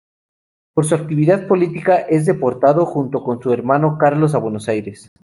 Read more a male given name, equivalent to English Charles Frequency B2 Hyphenated as Car‧los Pronounced as (IPA) /ˈkaɾlos/ Etymology Inherited from Latin Carolus In summary Inherited from Latin Carolus, of Germanic origin.